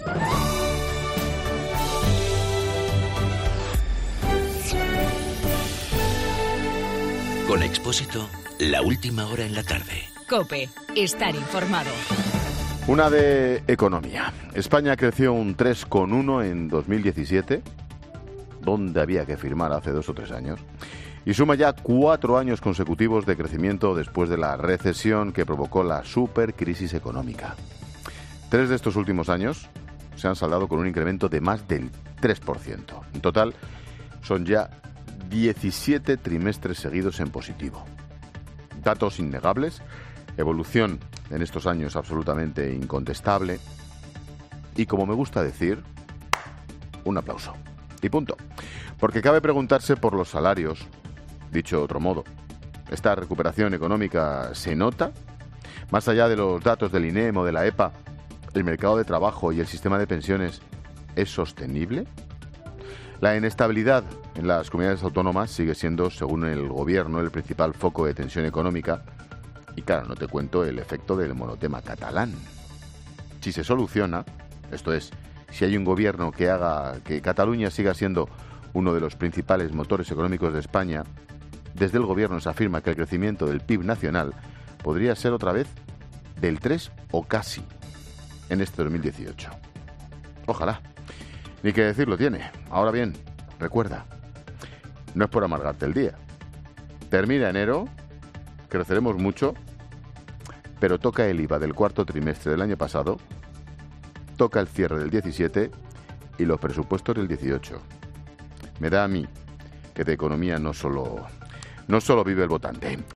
AUDIO: El comentario de Ángel Expósito sobre la "supuesta" recuperación económica.